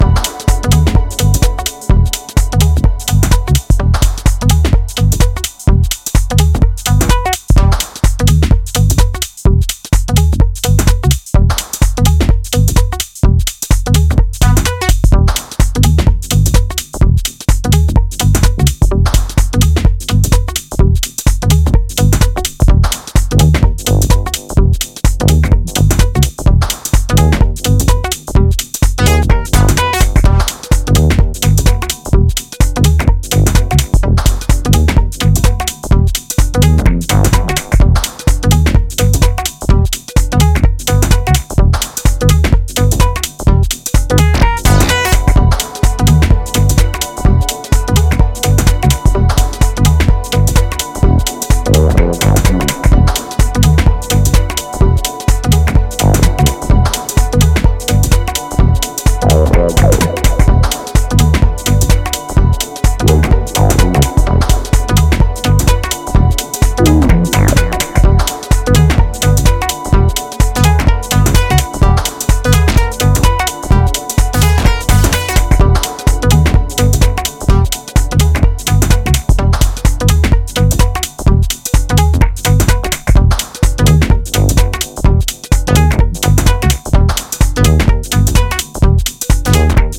its wonky sequences bending around rubbery low-end.